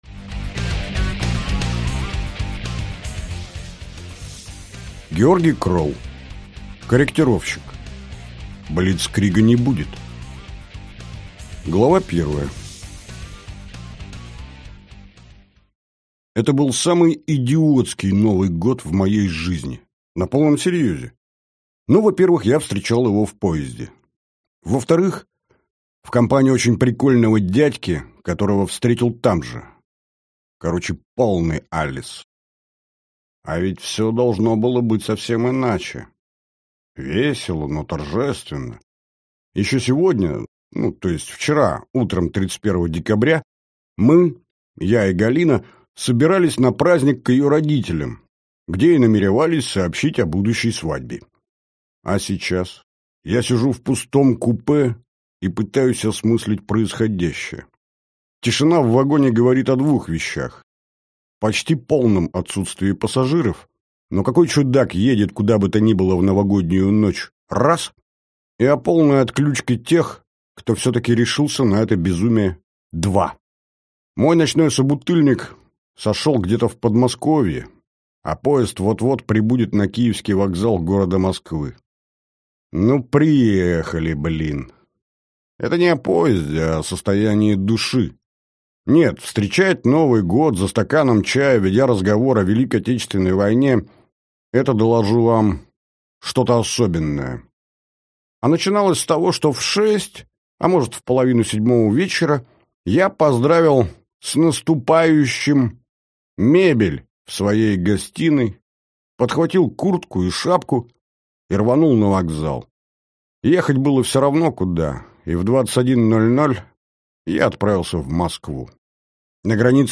Аудиокнига Корректировщик. Блицкрига не будет!